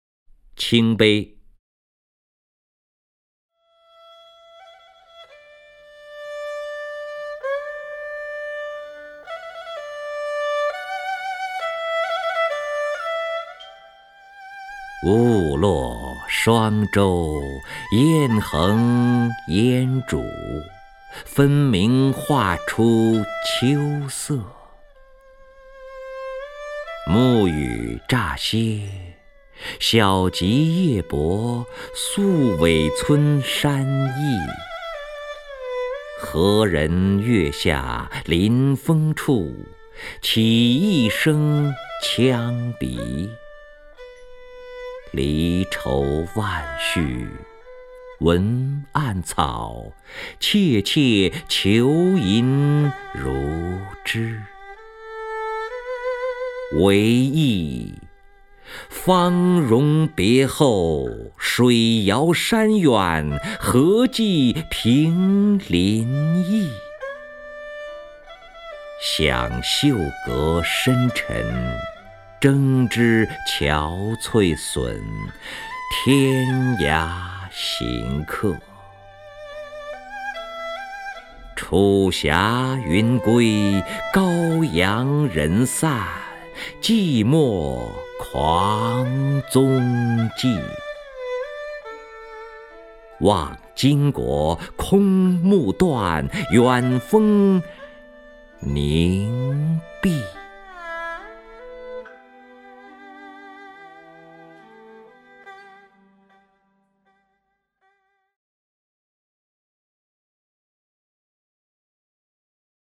任志宏朗诵：《倾杯·鹜落霜洲》(（北宋）柳永)